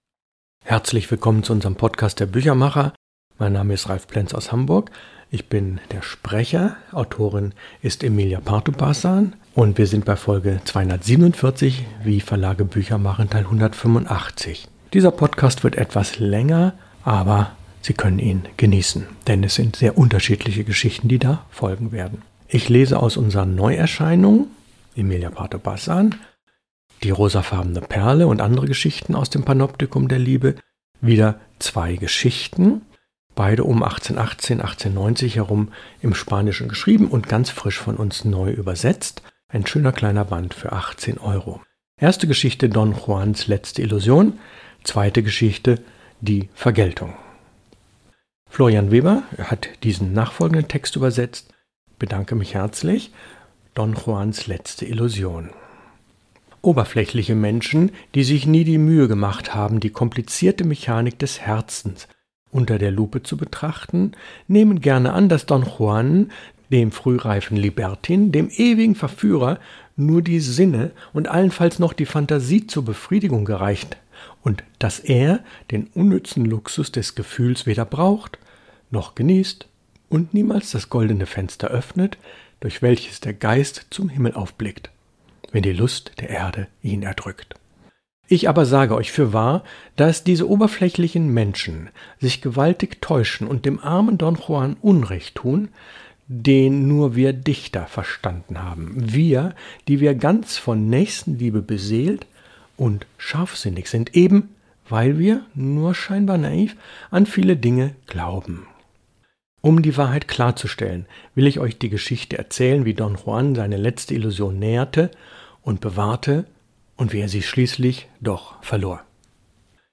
Lesung aus: Emilia Pardo Bazán – Die rosafarbene Perle ... aus dem Panoptikum der Liebe, Folge 2 von 3